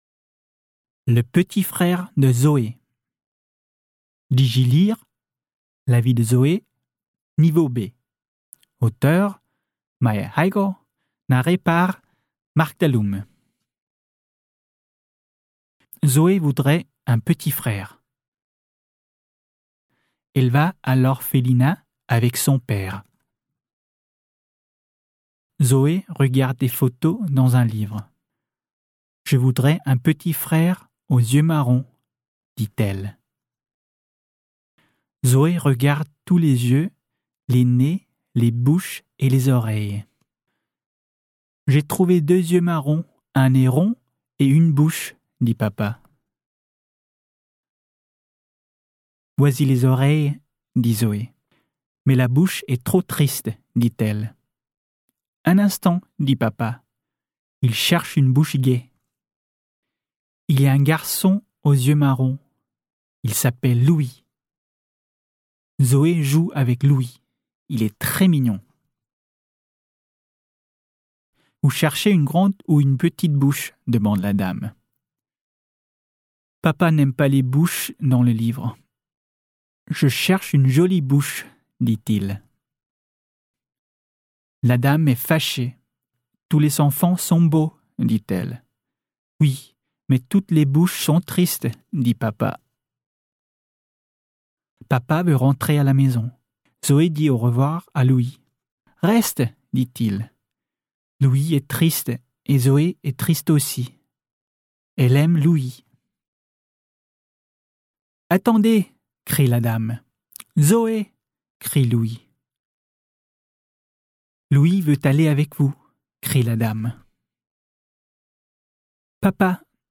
Scan QR-koden på side 2 med kameraet i din smartphone eller tablet og hør oplæsning af de tilhørende tekster af en indfødt franskmand.